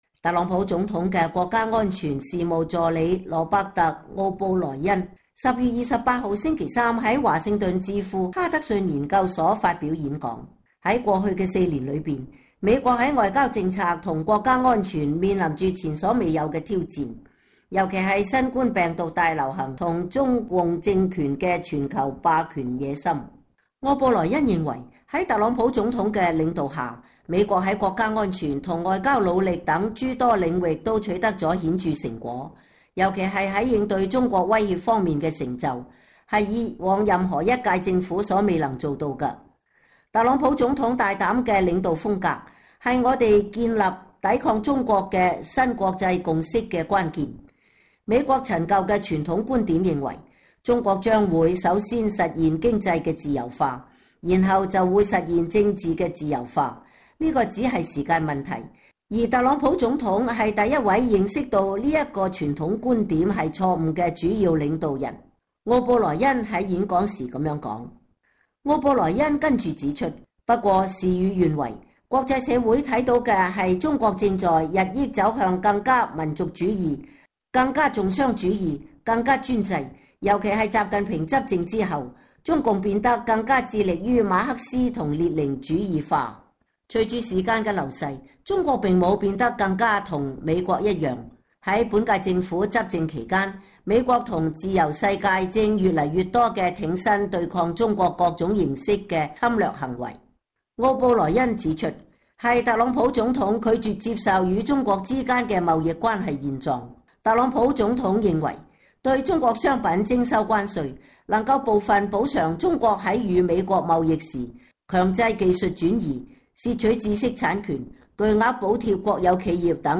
特朗普總統的國家安全事務助理羅伯特·奧布萊恩（Robert O’Brien），星期三（10月28日）在華盛頓智庫哈德遜研究所（Hudson Institute）發表演講。